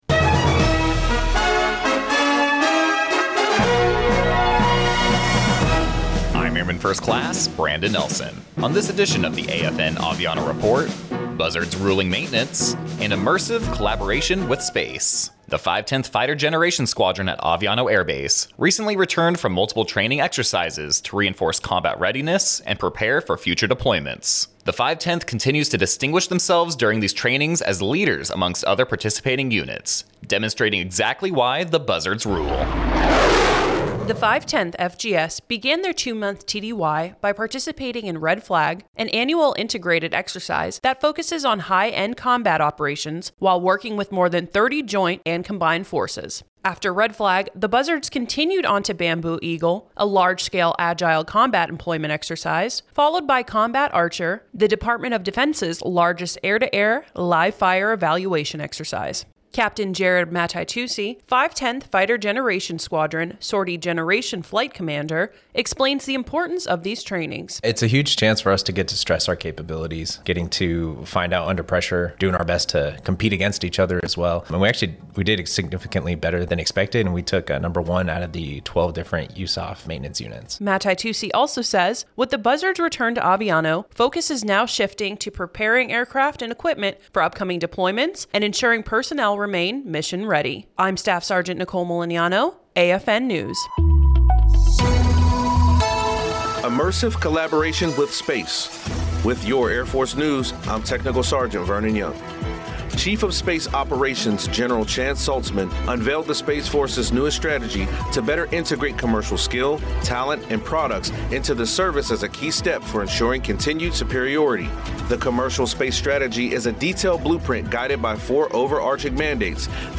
American Forces Network (AFN) Aviano radio news reports on the 510th Fighter Generation Squadron’s recent participation in exercises Red Flag, Bamboo Eagle and Combat Archer in preparation for upcoming deployments.